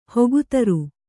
♪ hogu taru